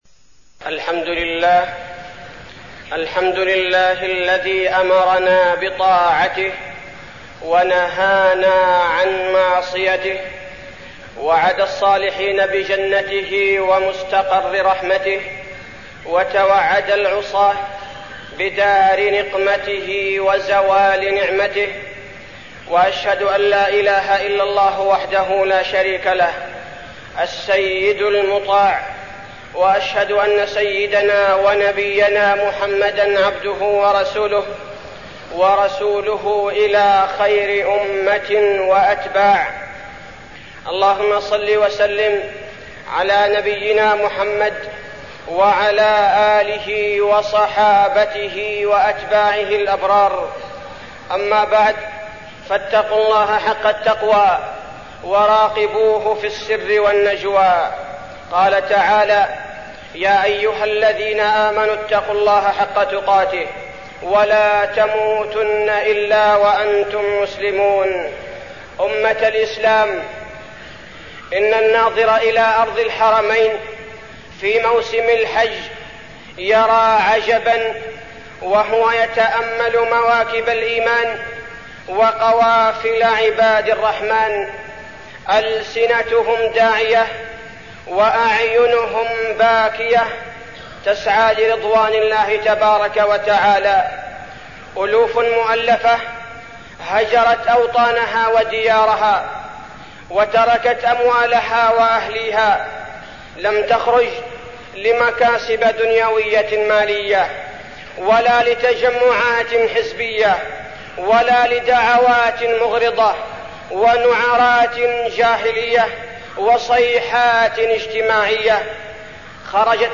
تاريخ النشر ٤ ذو الحجة ١٤١٧ هـ المكان: المسجد النبوي الشيخ: فضيلة الشيخ عبدالباري الثبيتي فضيلة الشيخ عبدالباري الثبيتي فضل عشر ذي الحجة